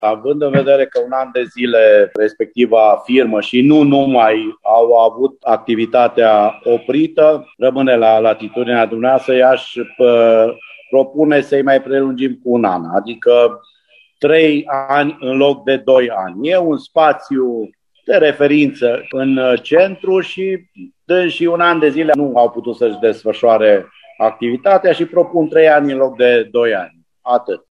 Dispute aprinse în Consiliul Local Timișoara pe marginea unui proiect de hotărâre care viza prelungirea contractului de închiriere pentru cafeneaua Simphony, aflată la parterul Operei Naționale din Timișoara.
Propunerea era de prelungire a contractului cu doi ani. Consilierul local Simion Moșiu a propus o prelungire cu trei ani.